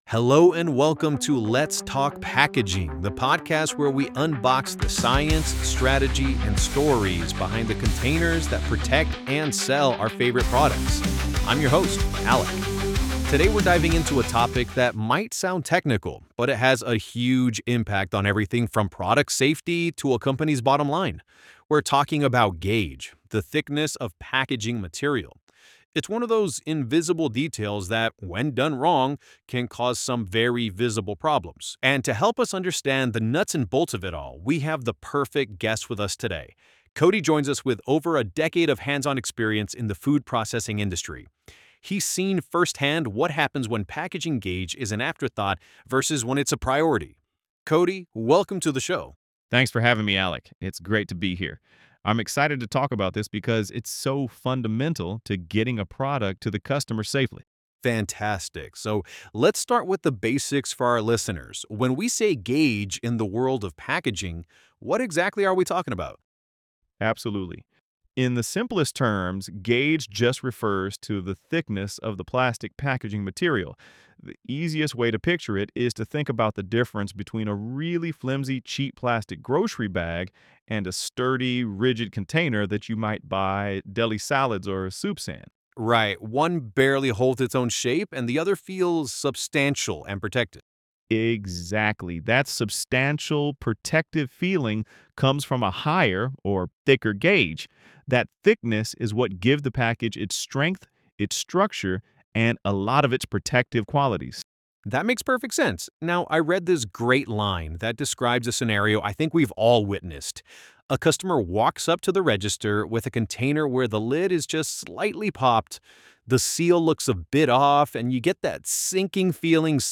This audio uses AI-generated content and media.